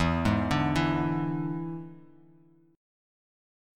E7 Chord
Listen to E7 strummed